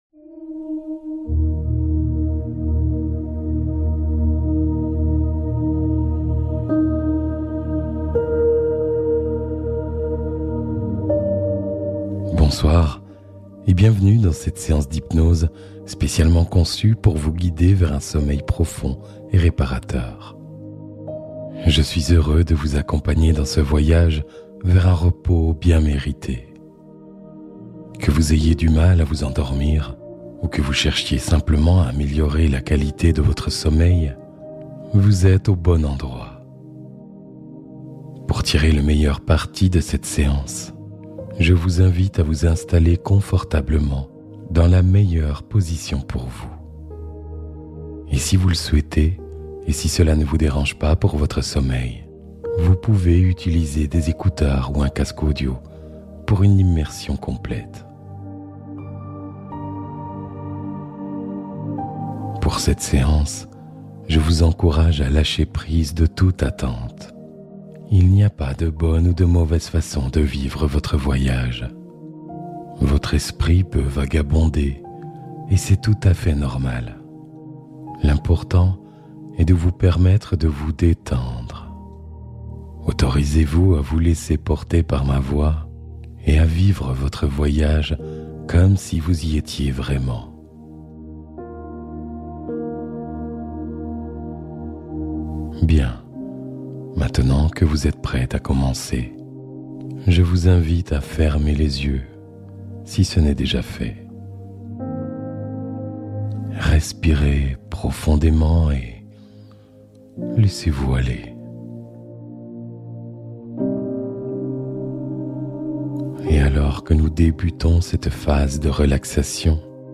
Sommeil Ininterrompu : Hypnose pour une nuit calme et stable